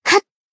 贡献 ） 分类:蔚蓝档案语音 协议:Copyright 您不可以覆盖此文件。
BA_V_Shiroko_Battle_Shout_2.ogg